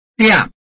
臺灣客語拼音學習網-客語聽讀拼-饒平腔-入聲韻
拼音查詢：【饒平腔】diab ~請點選不同聲調拼音聽聽看!(例字漢字部分屬參考性質)